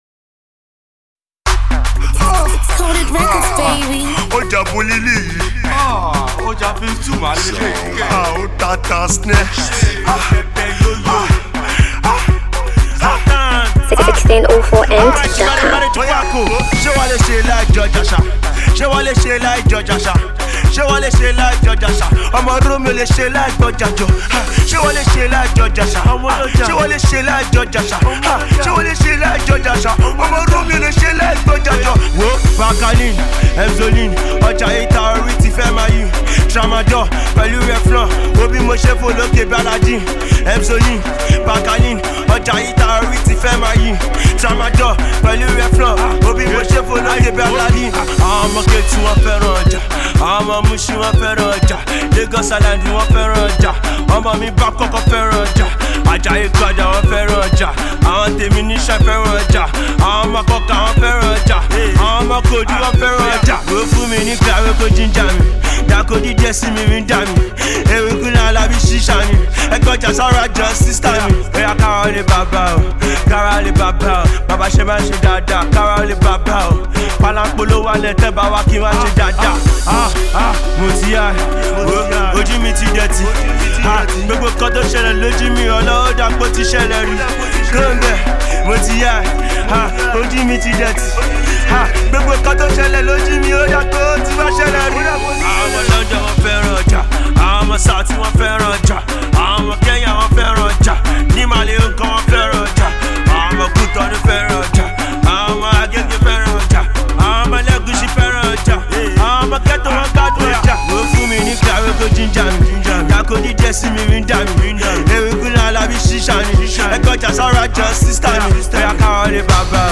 The Yoruba Rapper